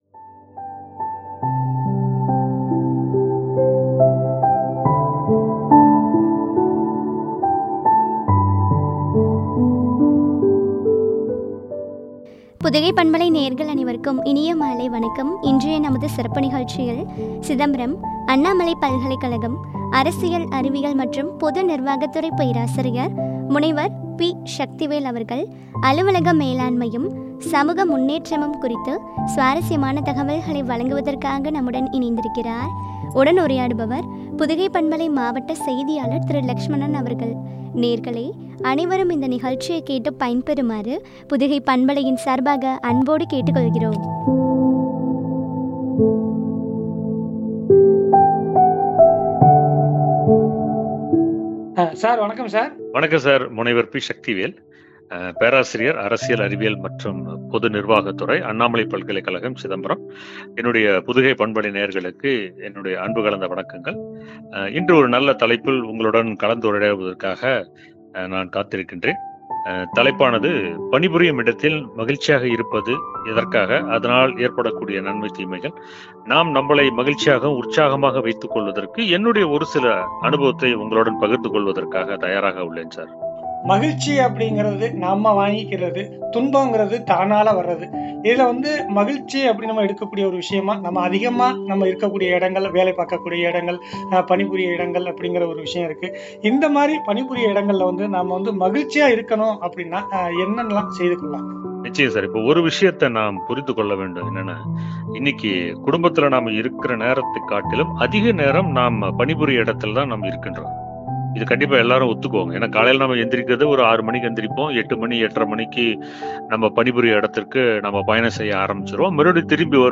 ” அலுவலக மேலாண்மையும் சமூக முன்னேற்றமும்” குறித்து வழங்கிய உரையாடல்.